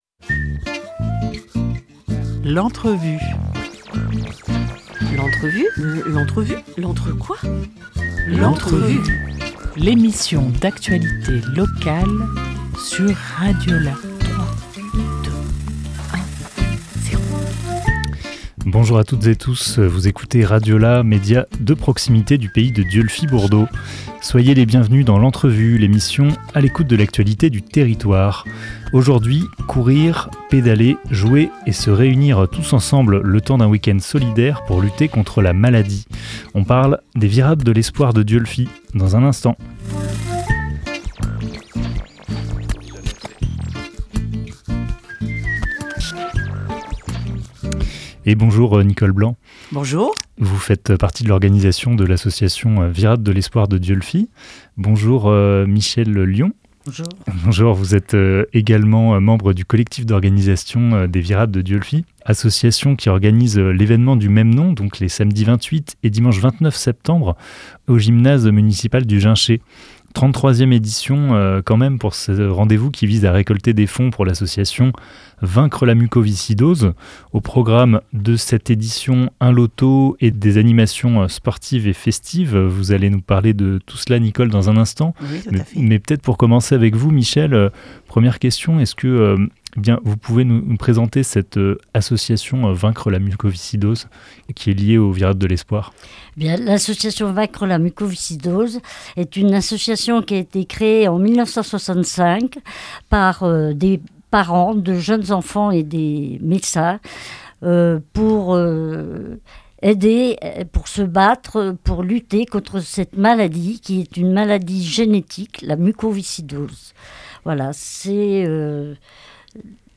19 septembre 2024 10:50 | Interview